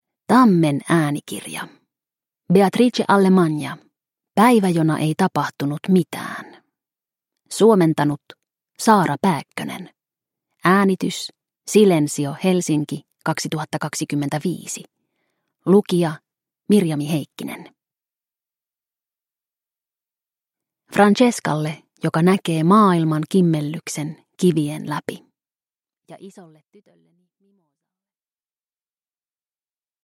Päivä jona ei tapahtunut mitään – Ljudbok